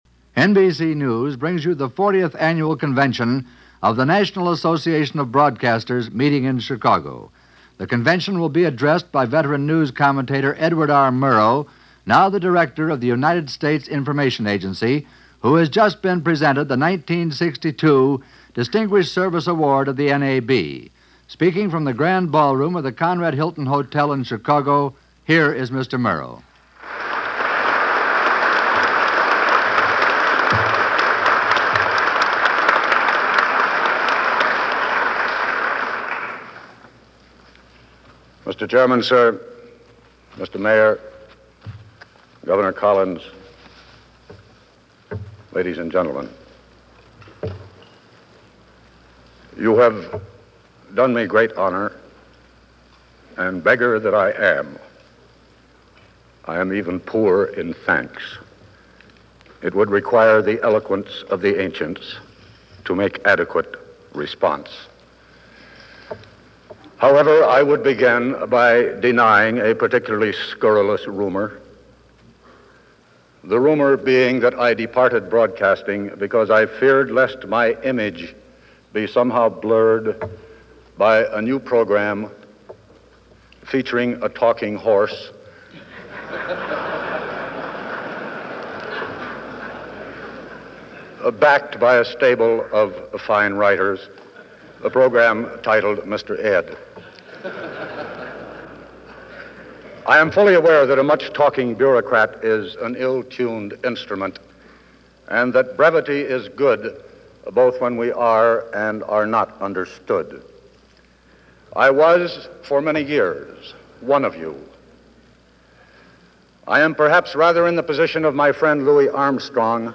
Edward R. Murrow Addresses The NAB Convention - 1962 - Past Daily Reference Room